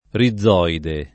rizoide [ ri zz0 ide ]